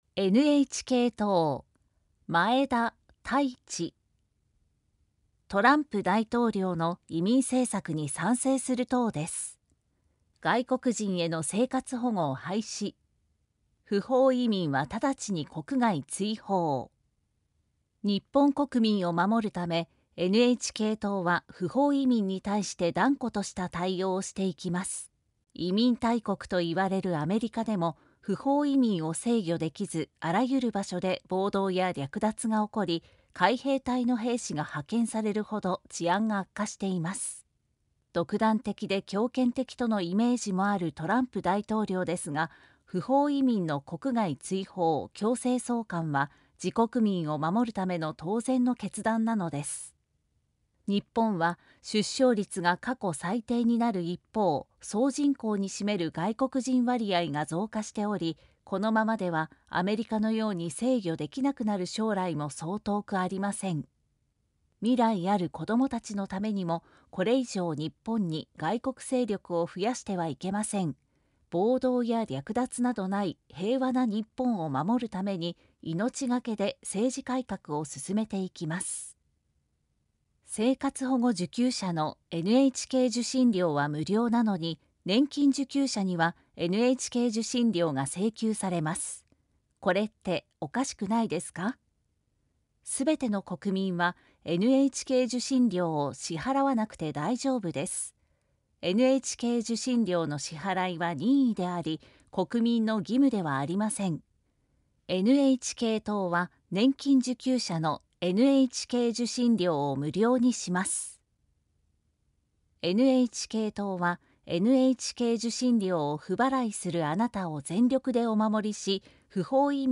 参議院議員通常選挙候補者・名簿届出政党等情報（選挙公報）（音声読み上げ用）（音声版）